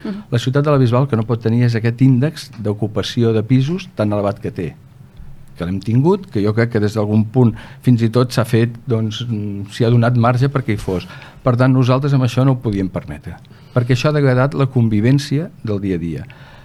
L’Oficina Antiocupacions que l’Ajuntament de la Bisbal d’Empordà va crear fa poc més d’un any ha aturat una trentena d’ocupacions. Ho ha explicat l’alcalde del municipi, Oscar Aparicio, en el programa ‘Govern i Oposició’ de Ràdio Capital